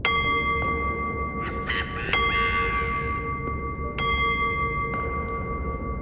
cuckoo-clock-03.wav